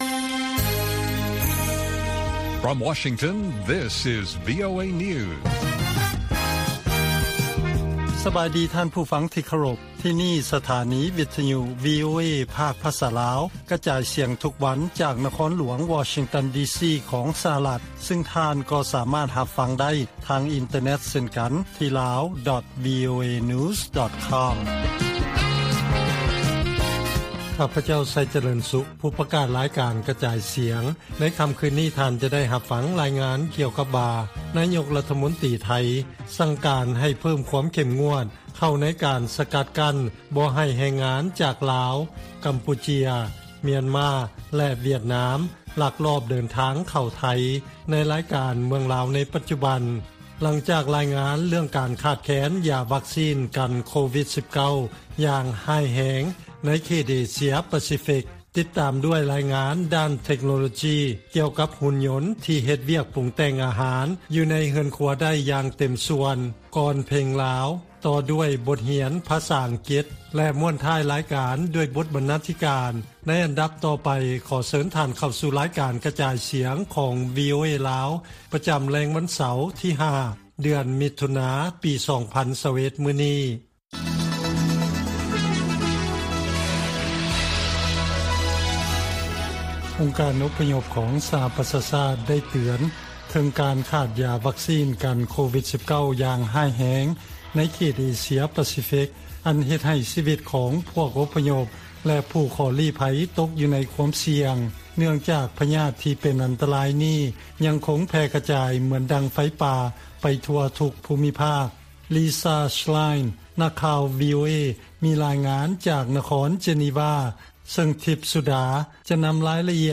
ວີໂອເອພາກພາສາລາວ ກະຈາຍສຽງທຸກໆວັນ. ຫົວຂໍ້ຂ່າວສໍາຄັນໃນມື້ນີ້ມີ: 1)ນາຍົກລັດຖະມົນຕີໄທ ເພີ້ມຄວາມເຂັ້ມງວດໃນການສະກັດກັ້ນ ເພື່ອບໍ່ໃຫ້ແຮງງານຈາກລາວ ກຳປູເຈຍ ຫວຽດນາມ ລັກລອບເດີນທາງເຂົ້າ ໄທ. 2) ເຮືອນຄົວທີ່ມີຫຸ່ນຍົນຄົວກິນ ອາດຈະປ່ຽນແປງ ການປຸງແຕ່ງອາຫານ ຢູ່ໃນເຮືອນ ແລະຮ້ານອາຫານຕ່າງໆ ໄດ້.